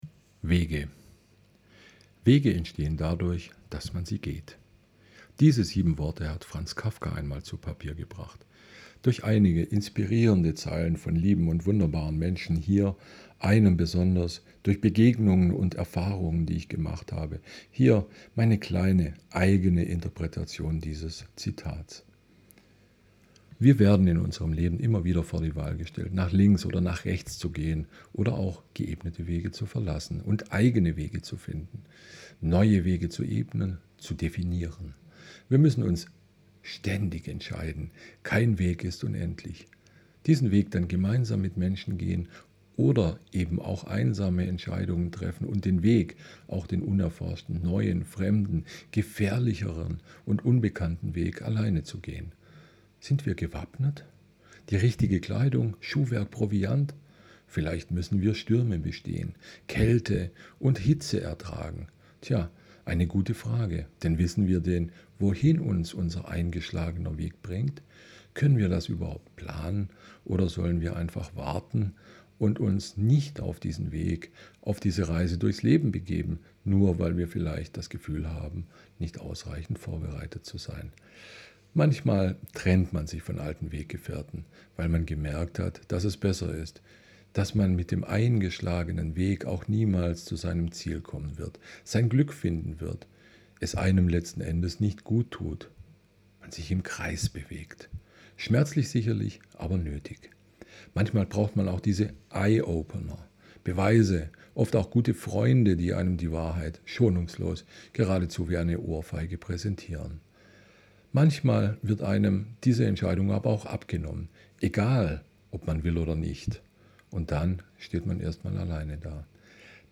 Mein Beitrag Wege als Hörbuch ?
4. Eine tolle Idee und eine schöne Stimme, sehr angenehm.
MacBook, MacOS, SHURE MV 51 das Mikro…
Deine Stimme ist angenehm und deutlich. Die Raumakustik ist ok.